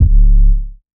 SOUTHSIDE_808_hood_C#.wav